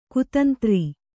♪ kutantri